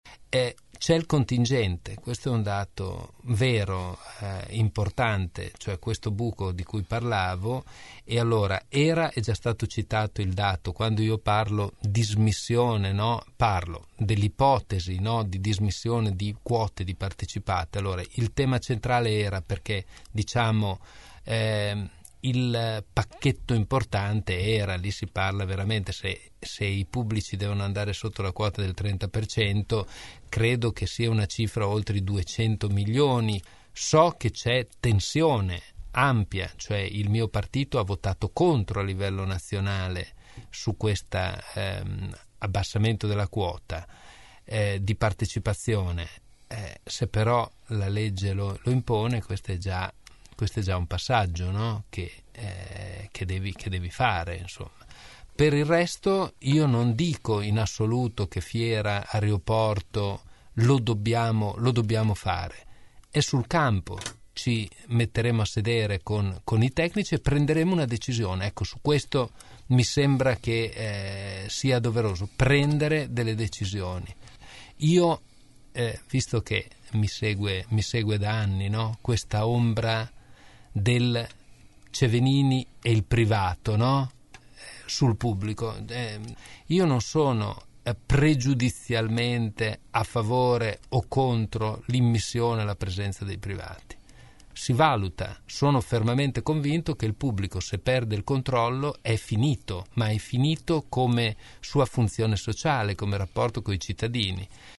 Dalla sala dibattiti centrale della Festa dell’Unità – “la prima volta da protagonista” – come afferma con orgoglio, ai nostri studi per un microfono aperto a caldo.